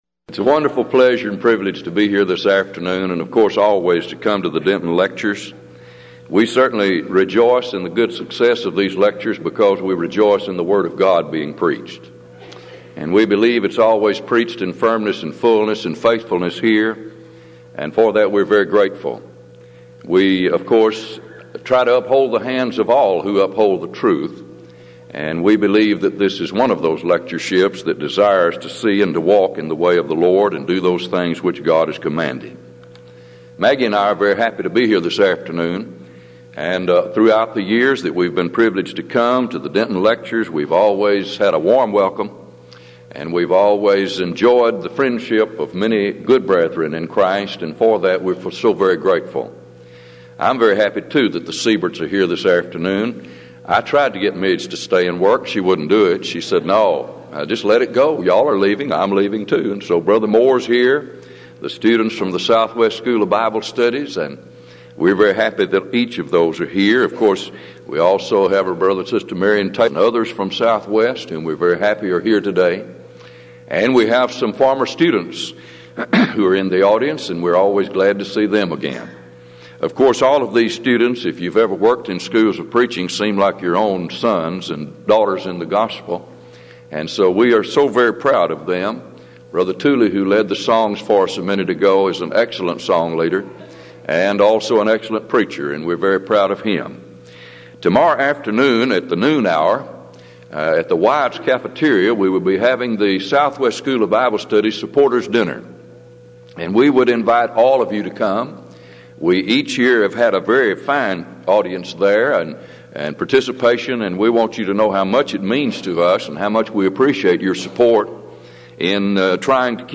Event: 16th Annual Denton Lectures Theme/Title: Studies In Ephesians
lecture